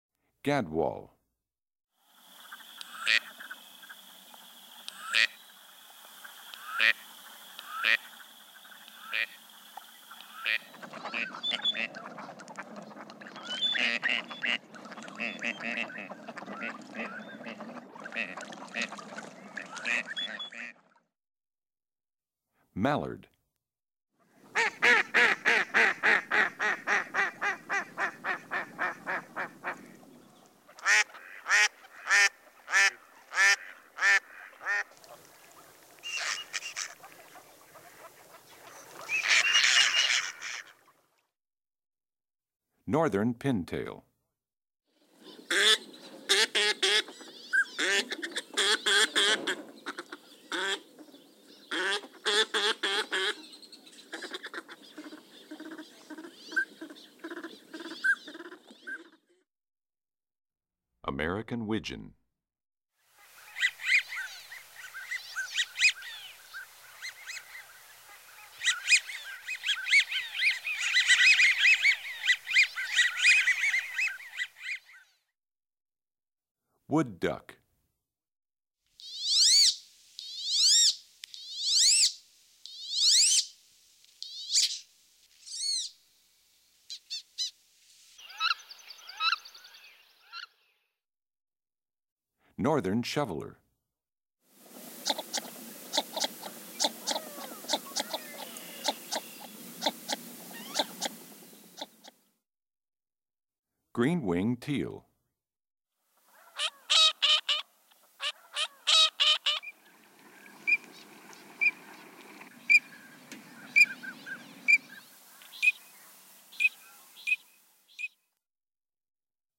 02 Gadwall,Mallard,Northern Pintail,American Wigeon,Wood Duck,Northern Shoveler,Green-winged Teal.mp3